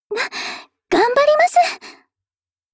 Cv-30183_battlewarcry_2.mp3 （MP3音频文件，总共长2.7秒，码率323 kbps，文件大小：109 KB）